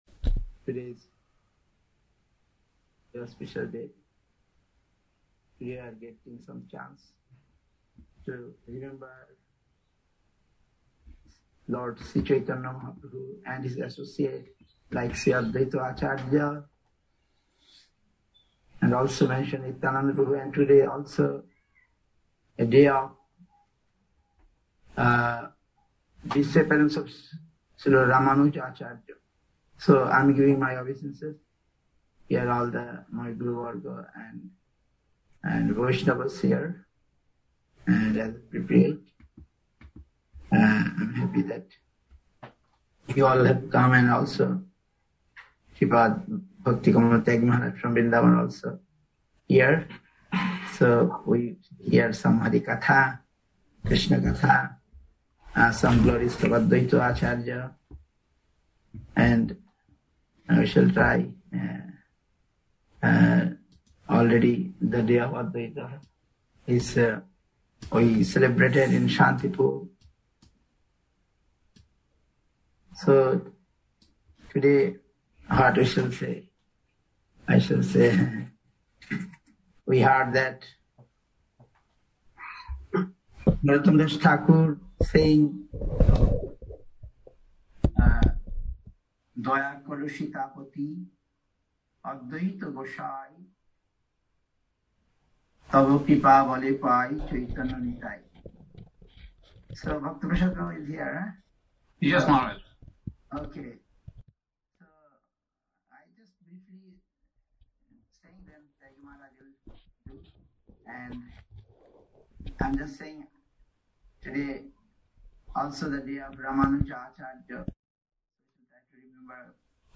Topic: Sri Advaita Probhu Apperence Day. 22.02.2021 India, Nabadwip, Kulia Gram, SREE CAITANYA SRIDHAR SEVA ASHRAM.